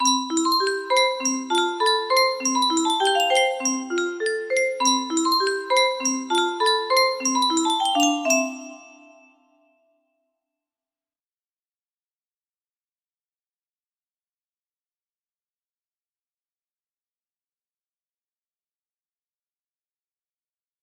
Juego no.1 music box melody